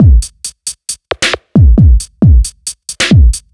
Phat Tr Break 135.wav